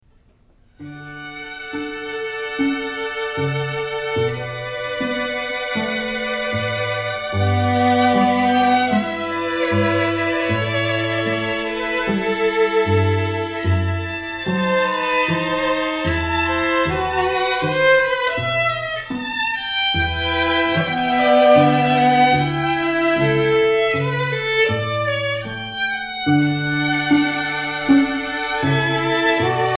String Celebrations USA offers performances by experienced, professional, classically trained musicians in the Greater Cincinnati area.